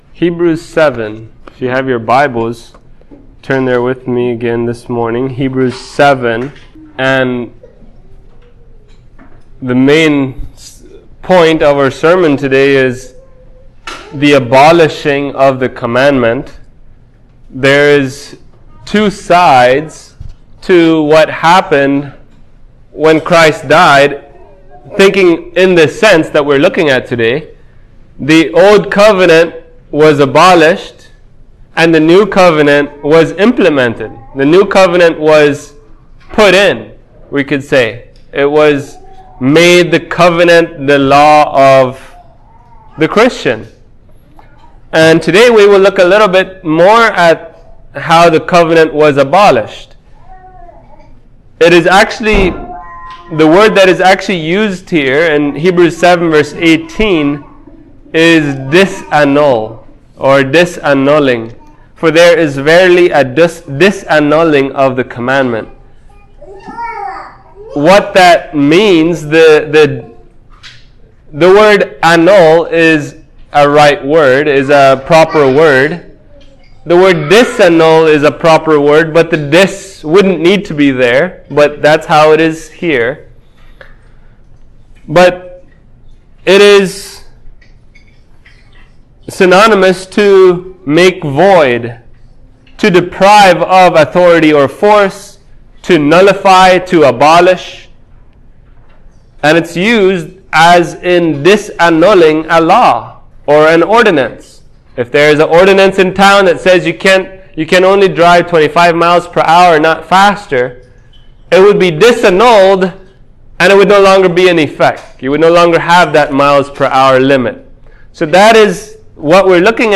Hebrews 7:17-28 Service Type: Sunday Morning How does the Law apply to Believers of the New Covenant?